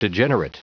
Prononciation du mot degenerate en anglais (fichier audio)
Prononciation du mot : degenerate